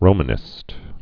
(rōmə-nĭst)